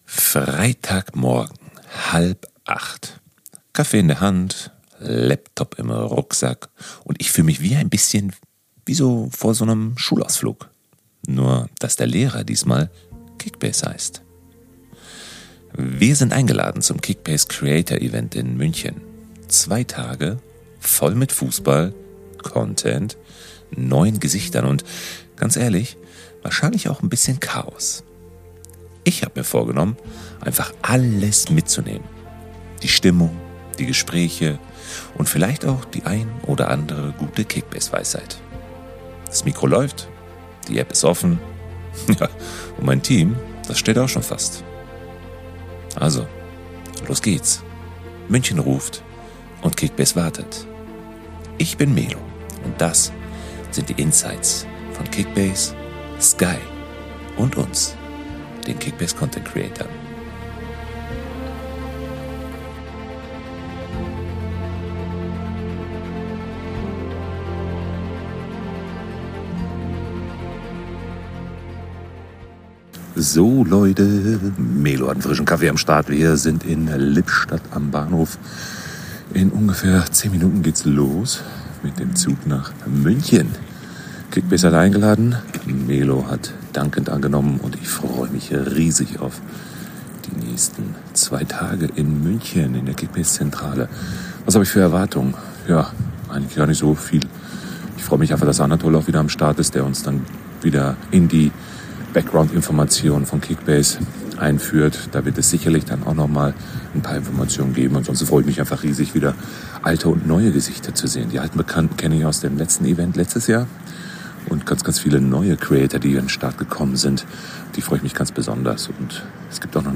Wir zeigen euch, wie Kickbase hinter den Kulissen funktioniert – ehrlich, persönlich, mit vielen O-Tönen, spannenden Gesprächen und jeder Menge Leidenschaft.